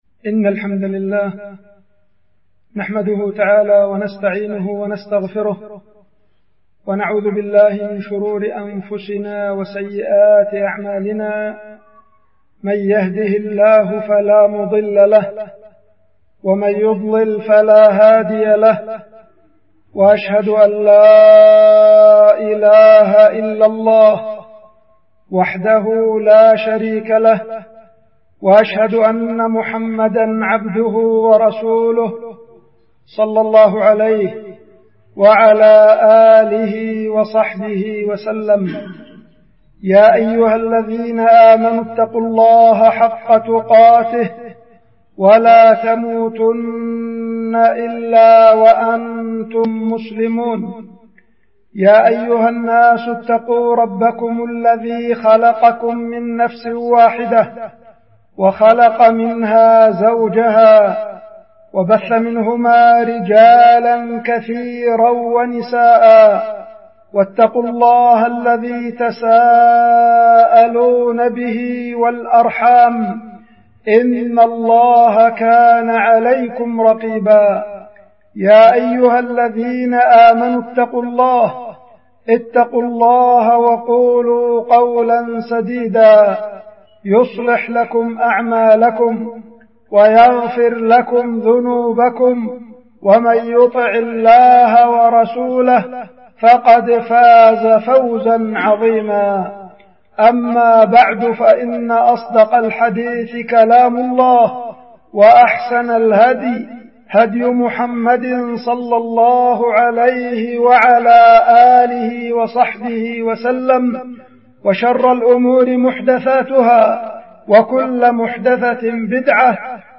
خطبة
القيت في مسجد شوط الفرس- مديرية بعدان- إب-اليمن